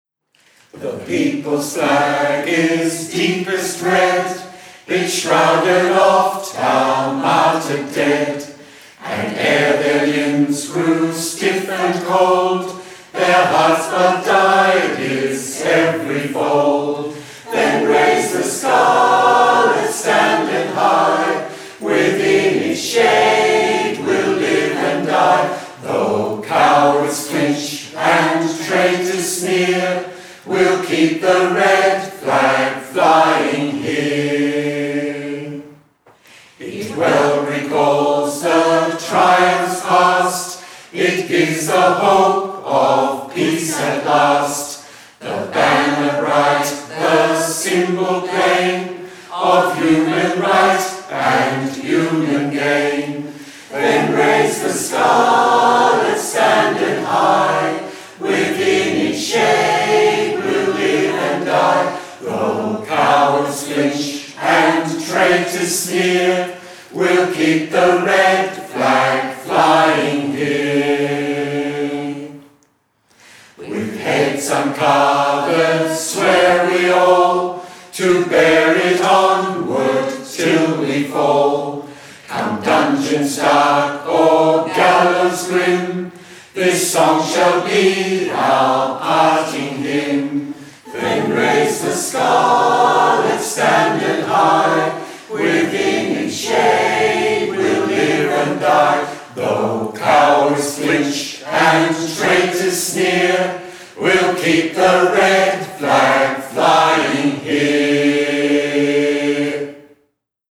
The recordings - The Victorian Trade Union Choir
The Victorian Trade Union Choir very kindly recorded some of these songs for the exhibition A Nation Divided: The Great War and Conscription.